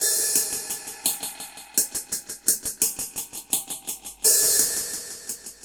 Db_DrumsA_HatsEcho_85_01.wav